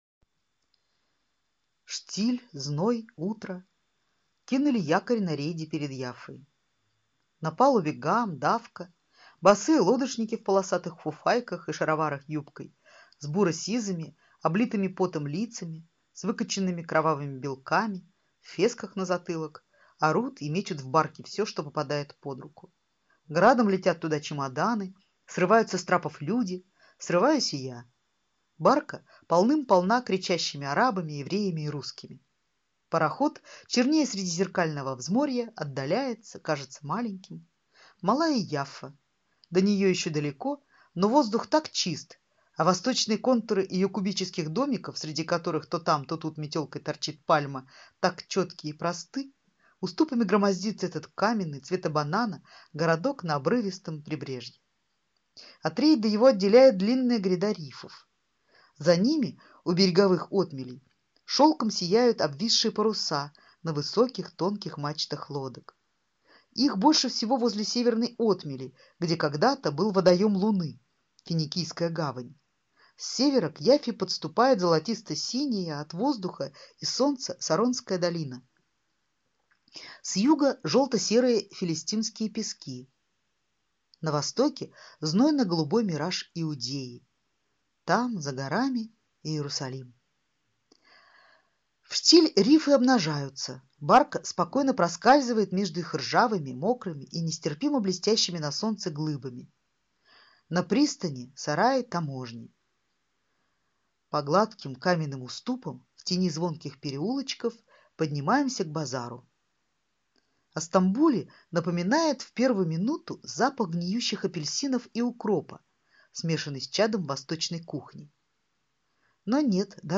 Aудиокнига Иудея